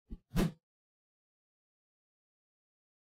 meleeattack-swoosh-heavy-group01-01.ogg